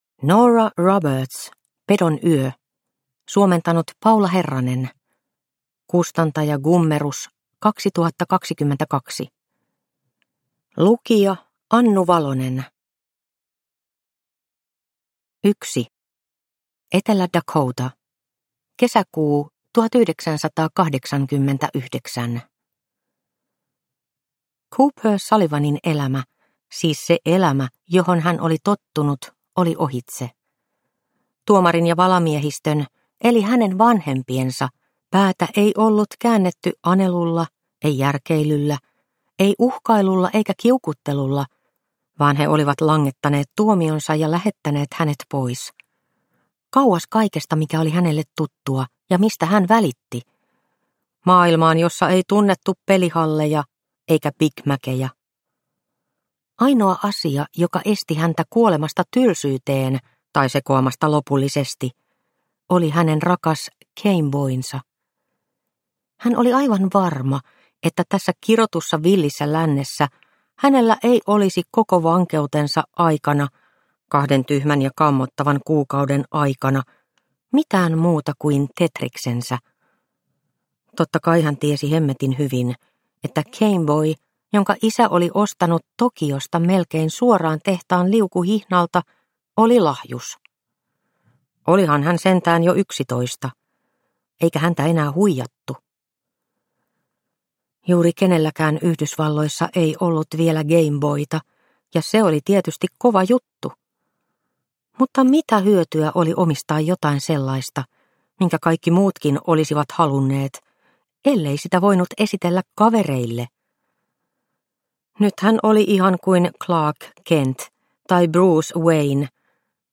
Pedon yö – Ljudbok – Laddas ner